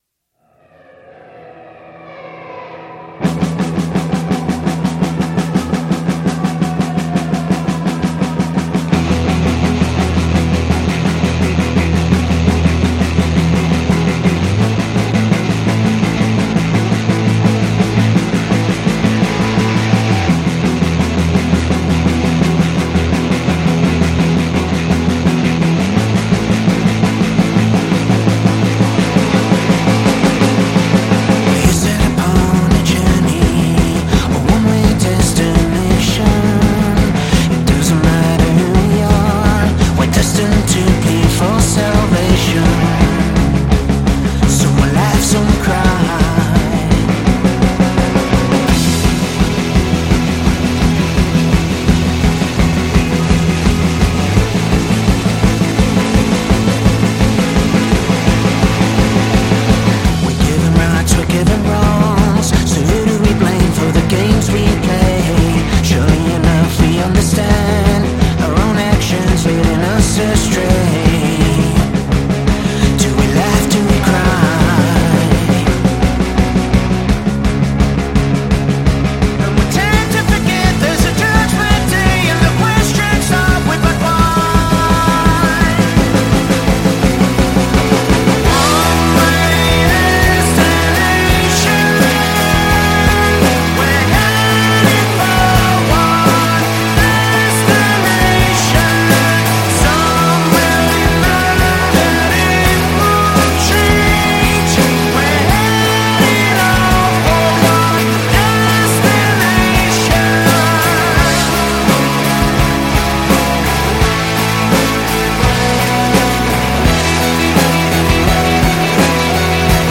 Australian Pop Rock Band